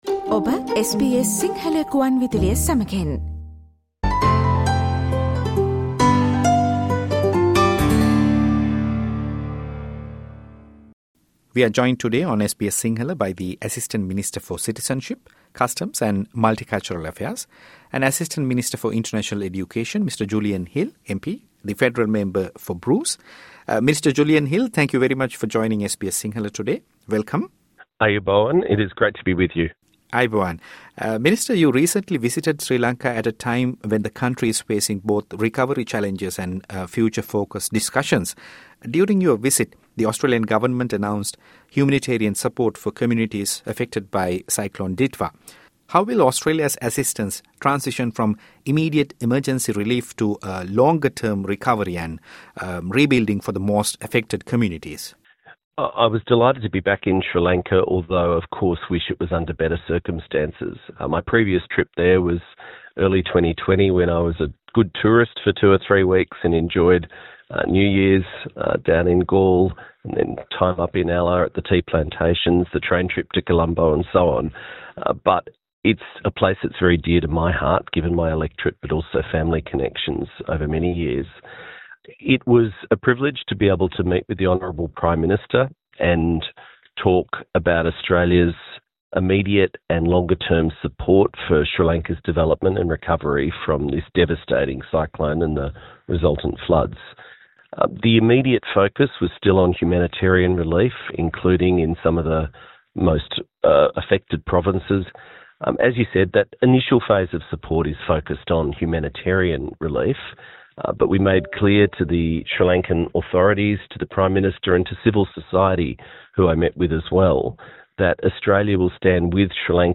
The Australian Federal Government’s Assistant Minister for Citizenship, Customs and Multicultural Affairs, and Assistant Minister for International Education, Julian Hill MP, who recently visited Sri Lanka, spoke to SBS Sinhala about his visit and the conditions faced by international students and multicultural communities following the Bondi attack.